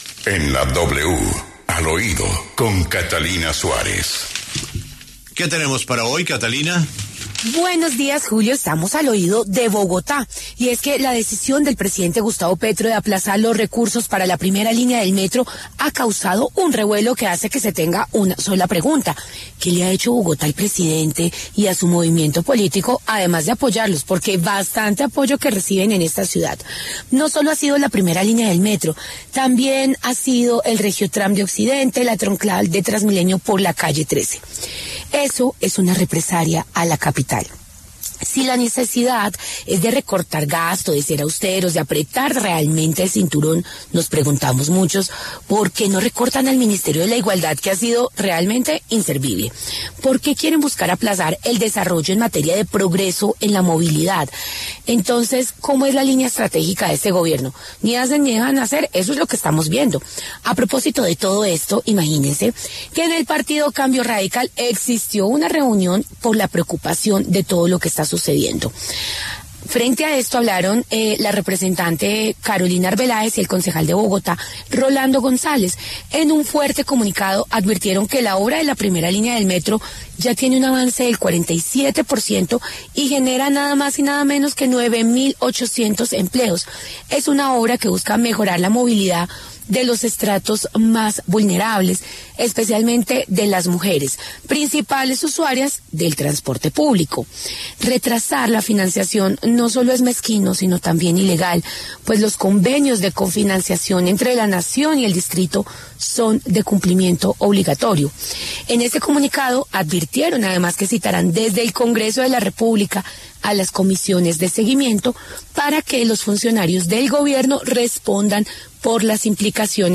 La representante Carolina Arbeláez y el concejal Rolando González proponen un frente común que defienda a Bogotá. El secretario de Gobierno, Gustavo Quintero, habla Al Oído.